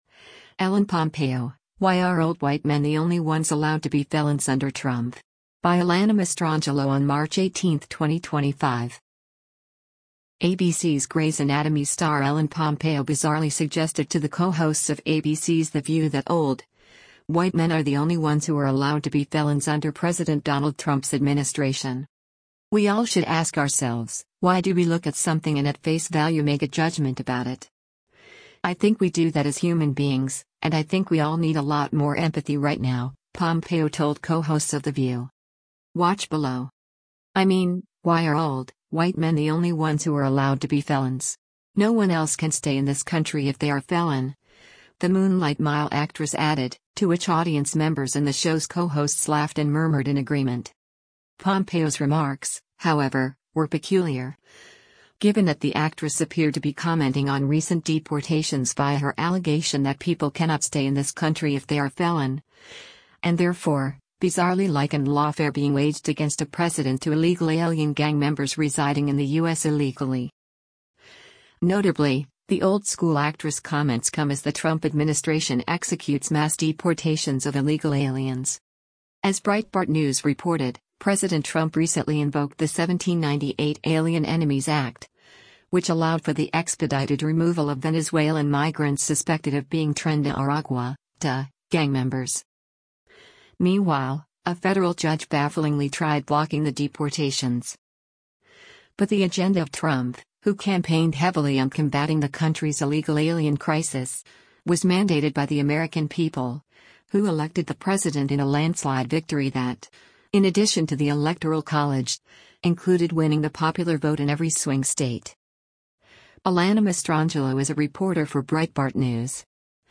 “I mean, why are old, white men the only ones who are allowed to be felons? No one else can stay in this country if they are a felon,” the Moonlight Mile actress added, to which audience members and the show’s co-hosts laughed and murmured in agreement.